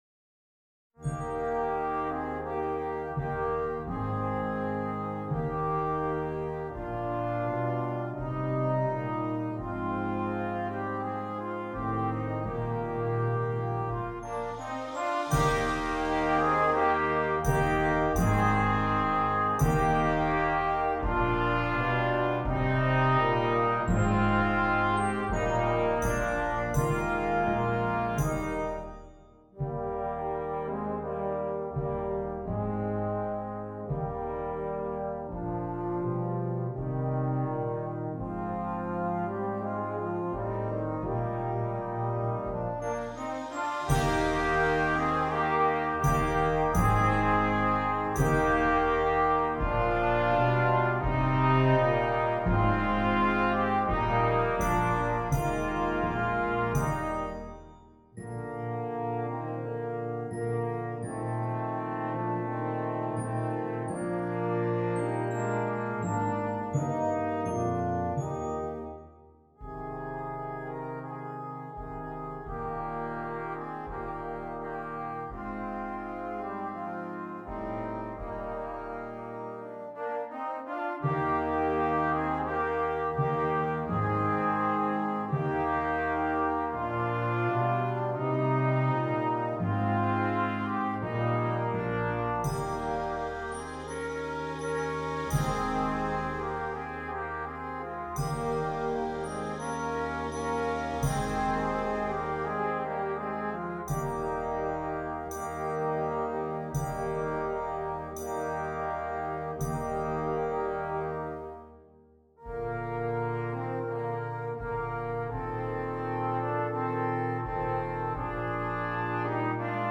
Brass Choir